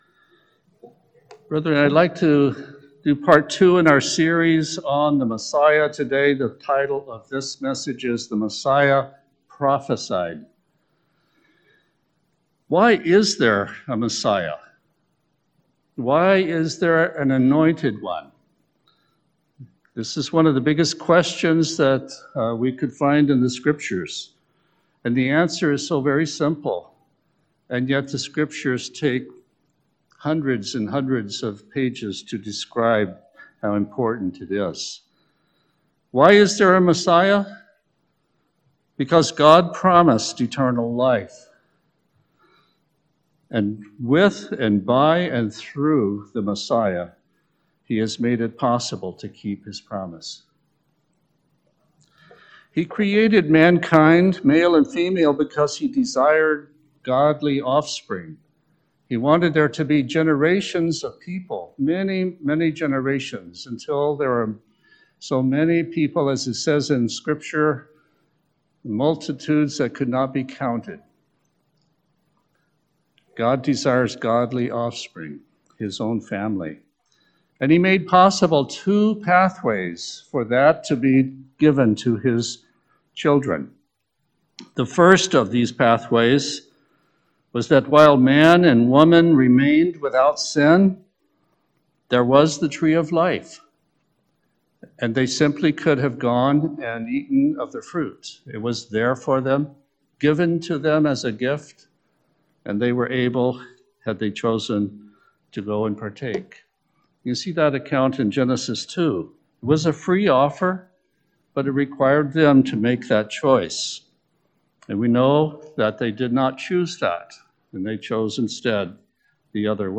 This sermon considers some of the many prophecies in the Old Testament that there would be a Messiah, and shows the fulfilling of those prophecies in the New Testament.